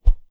Close Combat Swing Sound 2.wav